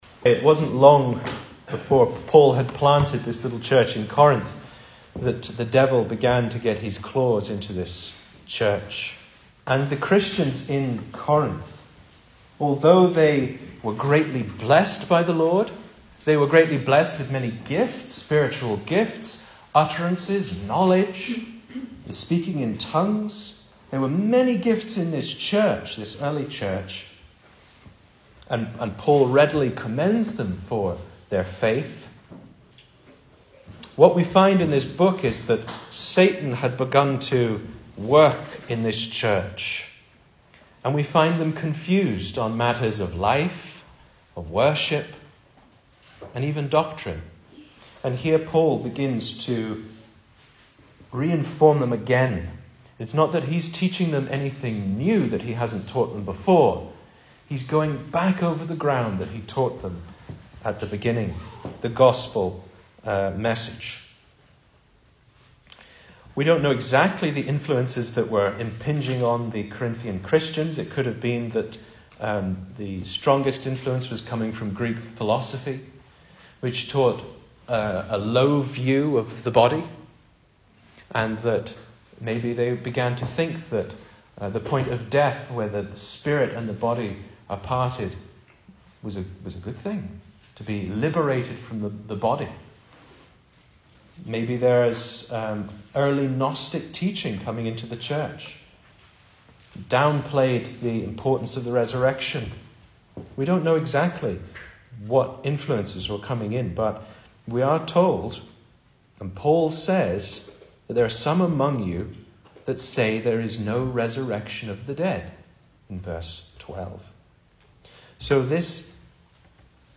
Service Type: Sunday Evening
Series: Single Sermons